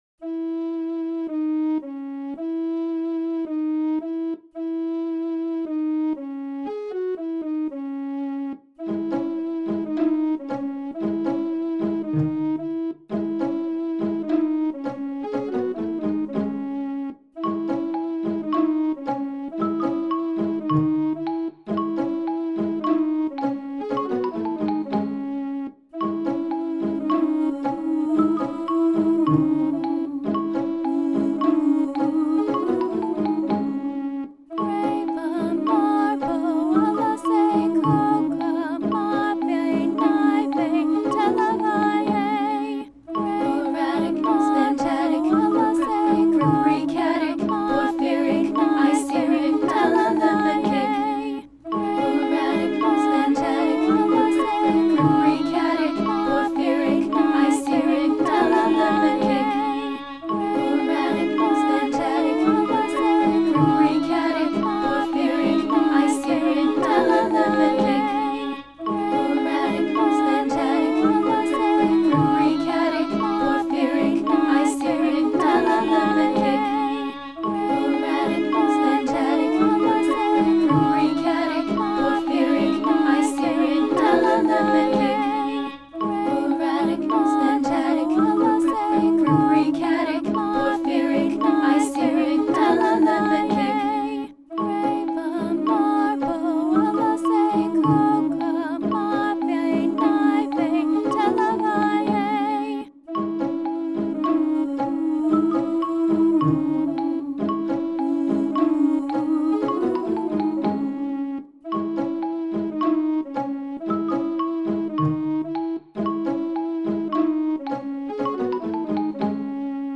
Posted in Dubstep
Vocals